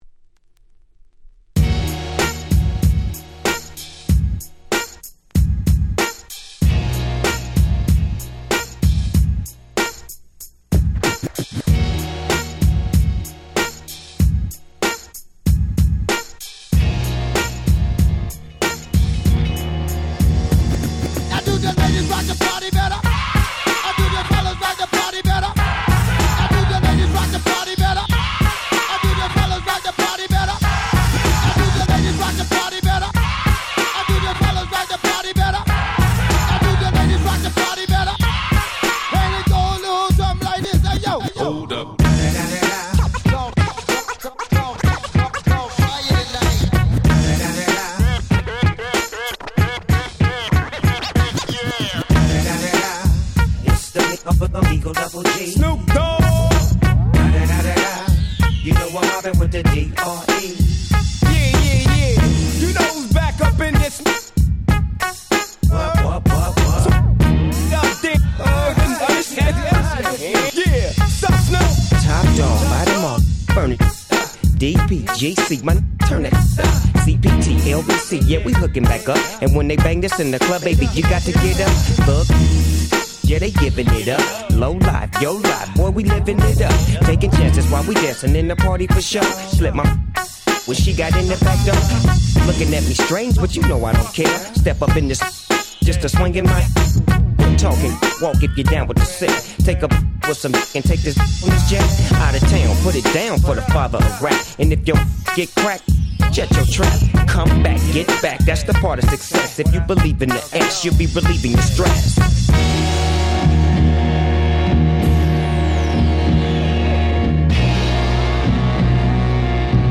【Media】Vinyl 12'' Single
【Condition】C (スリキズ多めですがDJ Play可。チリノイズ出ます。)